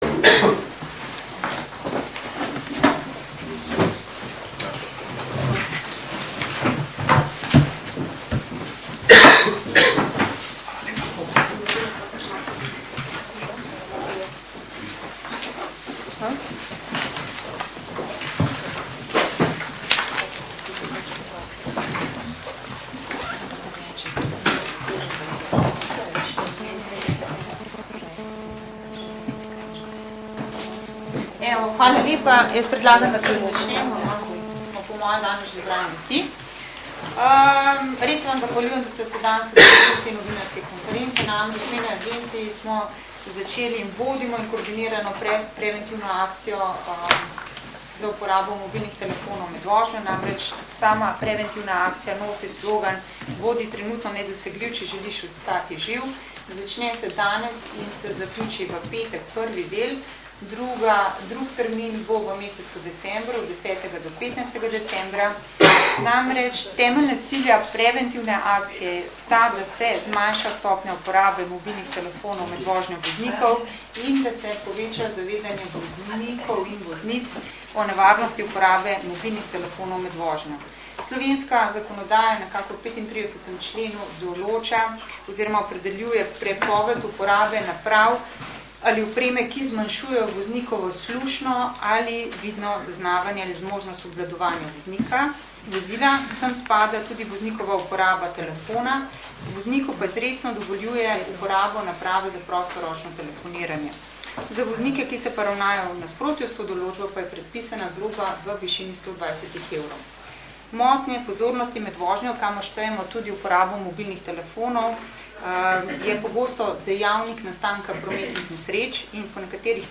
Dne 23.1.2012 je ob 11. uri na Javni agenciji RS za varnost prometa potekala novinarska konferenca z namenom predstavitve preventivne akcije, s katero bomo obveščali javnost o nevarnosti uporabe mobilnih telefonov med vožnjo.
Zvočni posnetek novinarske konference ikona mp3 (1485 kB)